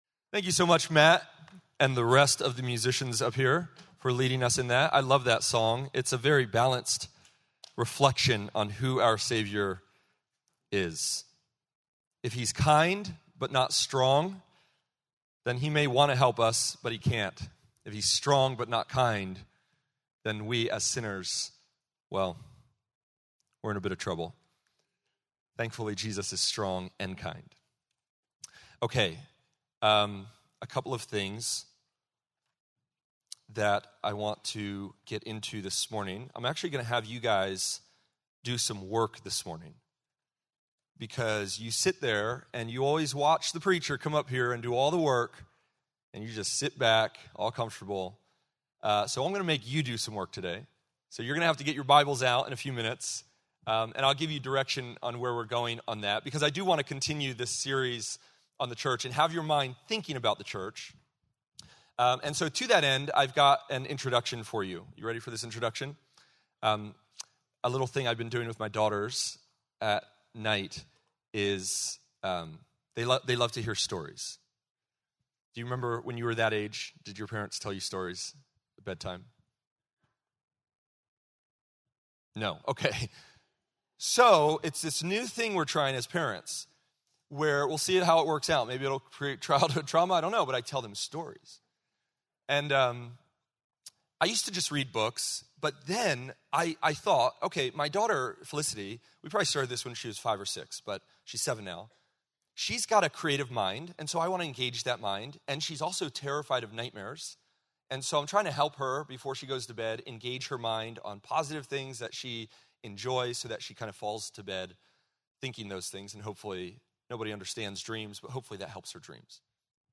September 7, 2025 - Sermon